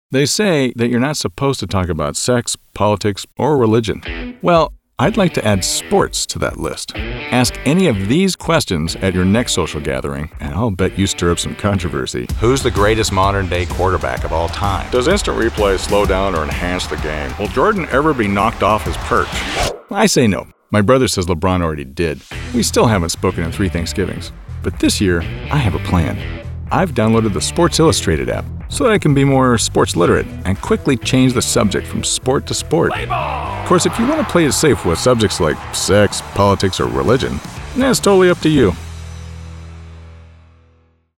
Commercial Demo
A voice actor with a smooth, trustworthy, and personable tone.
Fun, slightly alpha | Sports
• Microphone: Shure SM7B